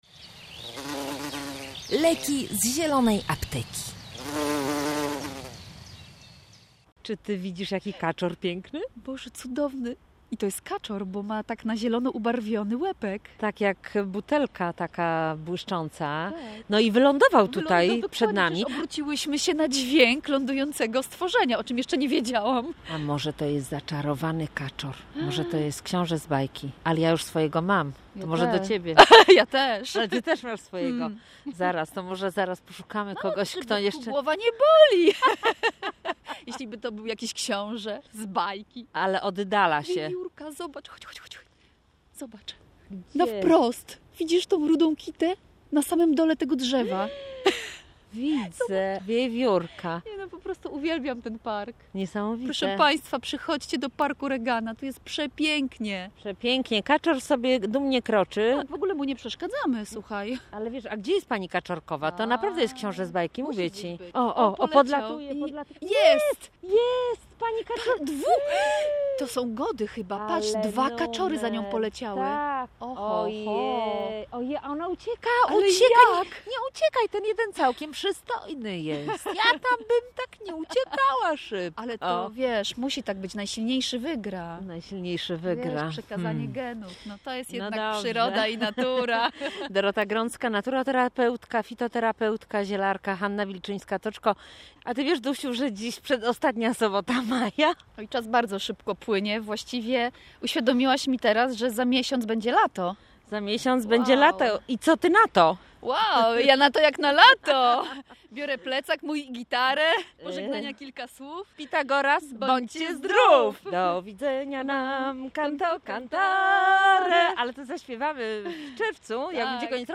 Panie też rozmawiają o preparatach „poślizgowych” i roli błonnika w spożywanych produktach.